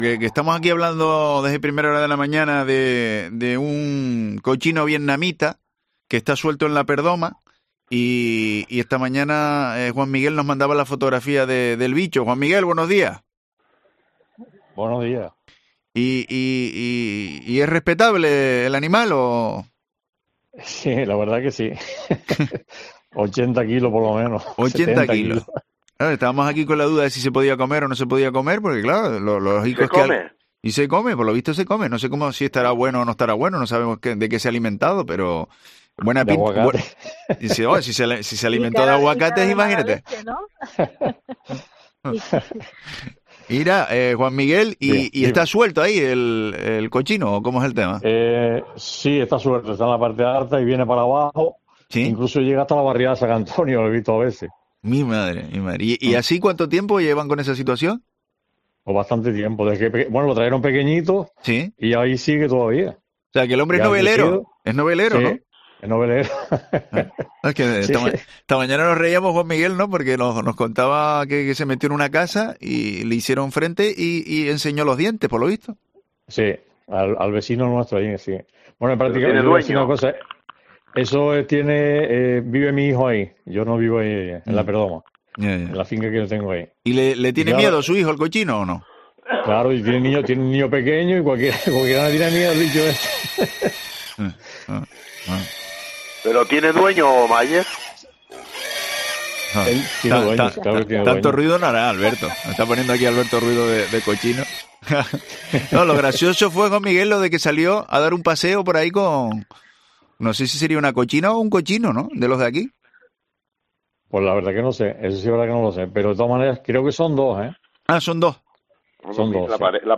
Un oyente relata a COPE Canarias cómo es el cerdo vietnamita que campa a sus anchas por La Perdoma